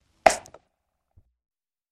На этой странице собрана коллекция звуков жевания жвачки.
Открывают паку с жвачками